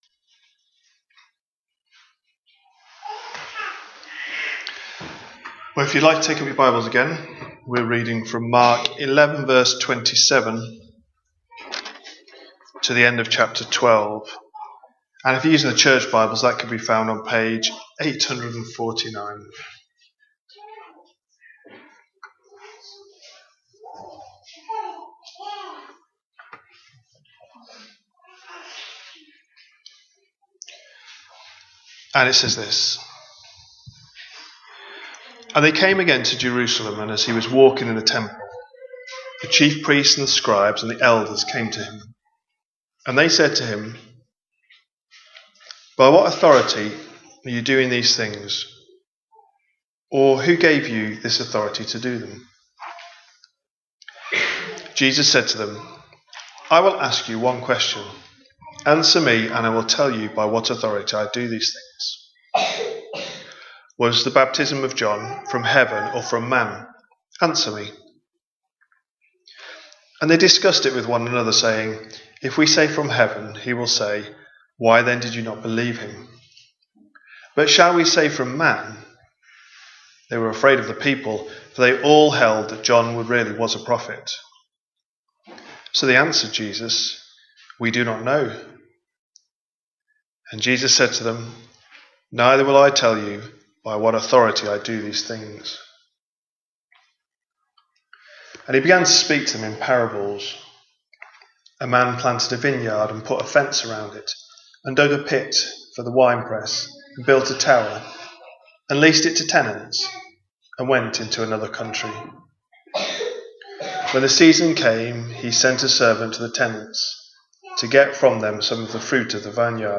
A sermon preached on 1st February, 2026, as part of our Mark 25/26 series.